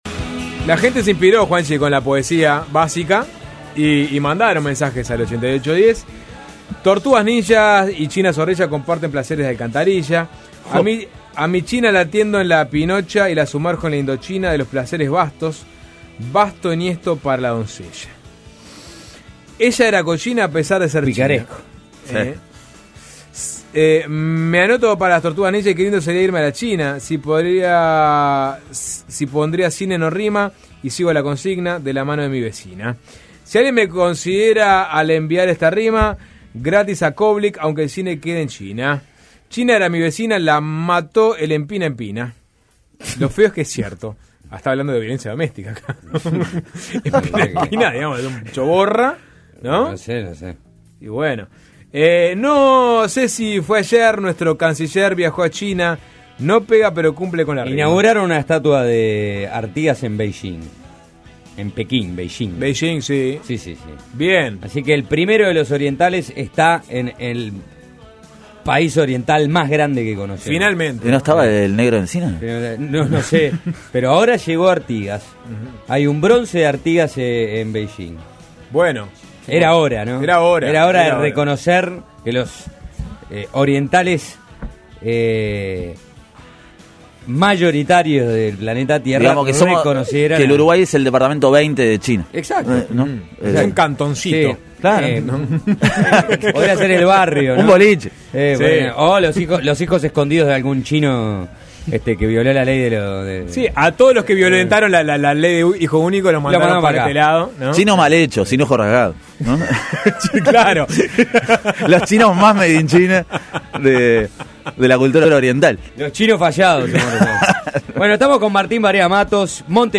Entrevistamos al poeta